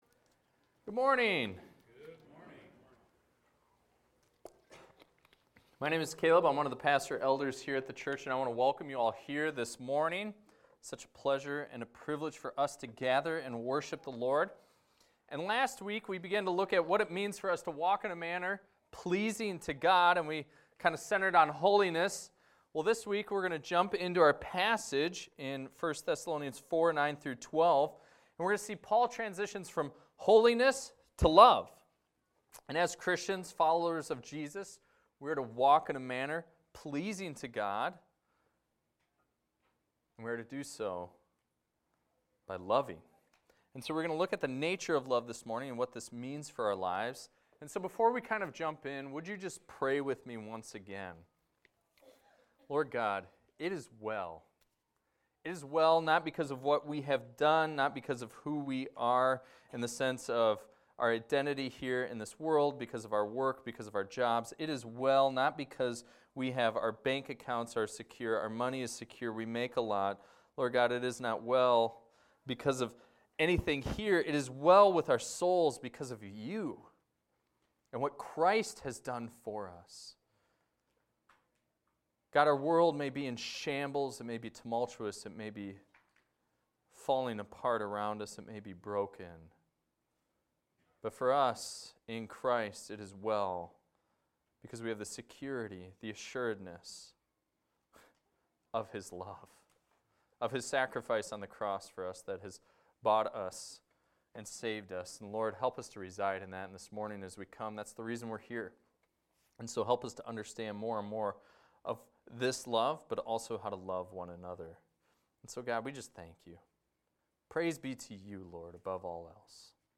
This is a recording of a sermon titled, "Brotherly Affection."